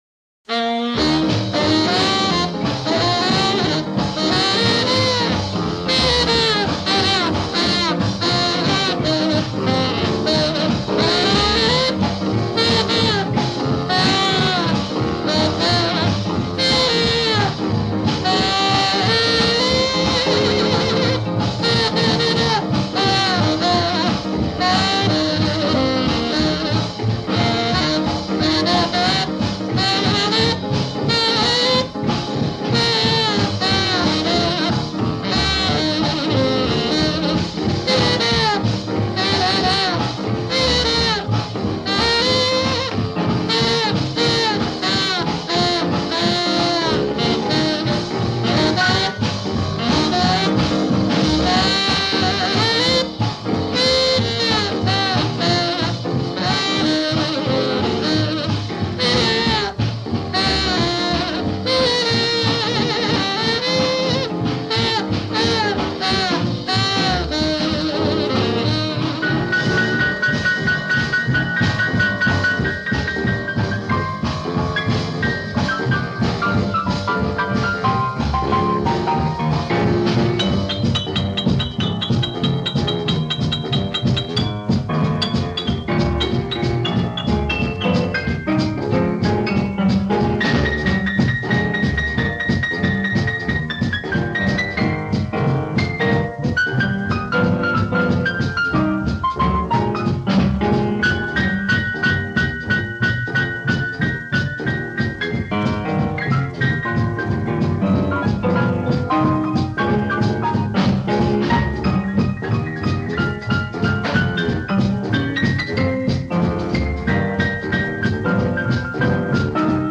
sax
piano